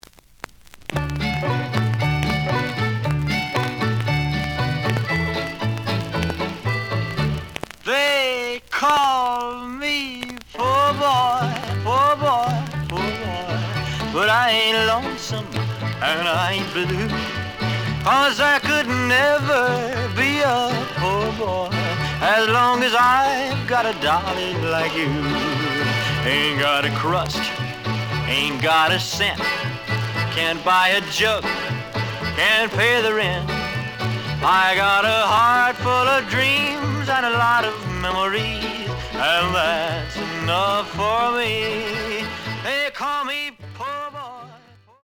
The audio sample is recorded from the actual item.
●Genre: Rhythm And Blues / Rock 'n' Roll
Some click noise on both sides due to scratches.